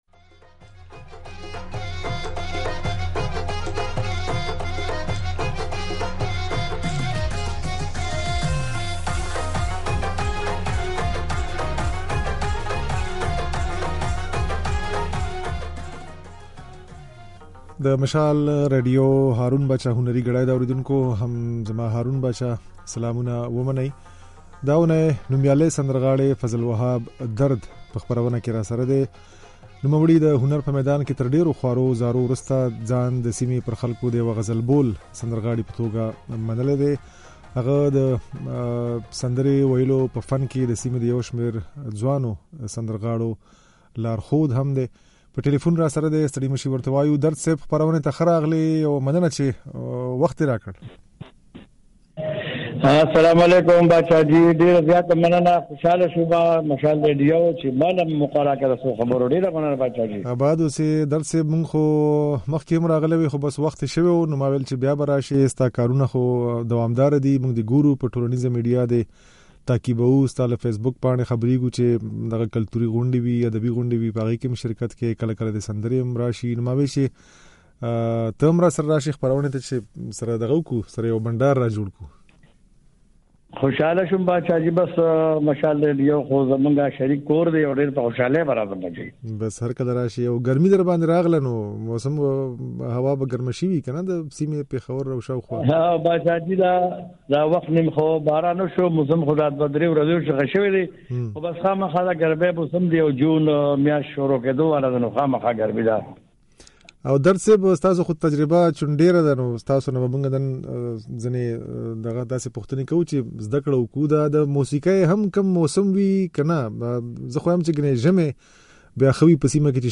د نوموړي دا خبرې او ځينې سندرې يې په خپرونه کې اورېدای شئ.